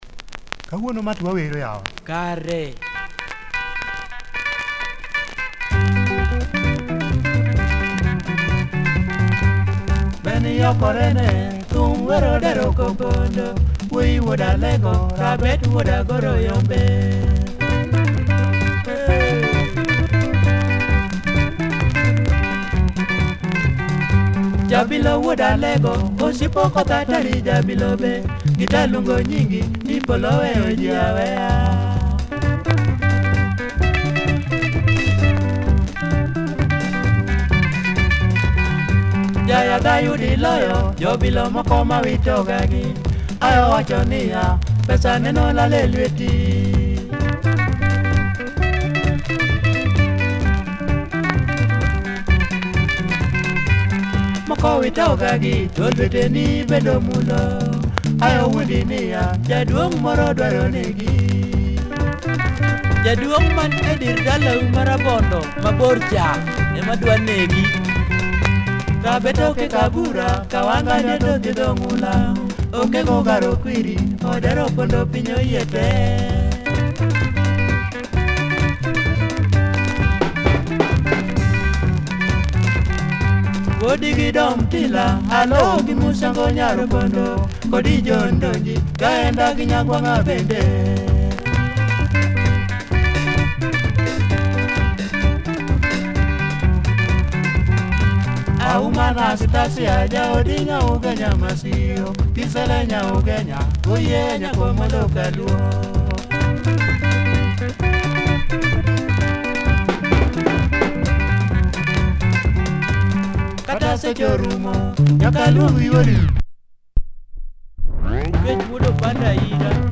Nice Luo Benga!